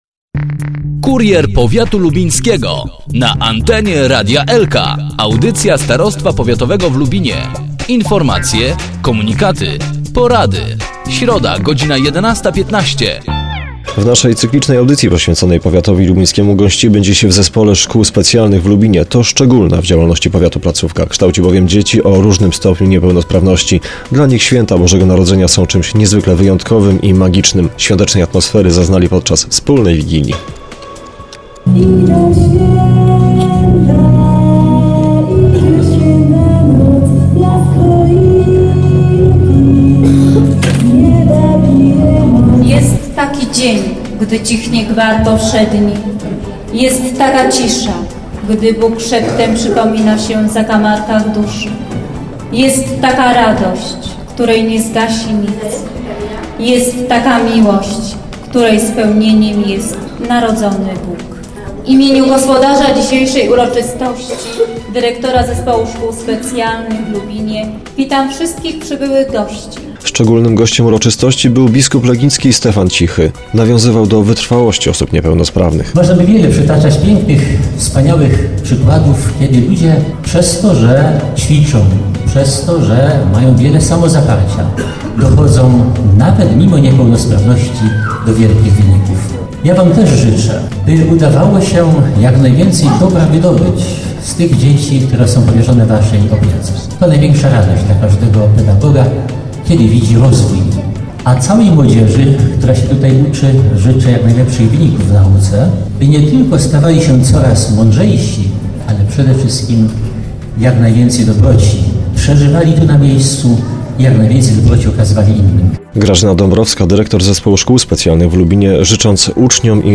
thumb_1216_biskup.jpgLubin. Niezwykle uroczysty charakter miała Wigilia w Zespole Szkół Specjalnych w Lubinie.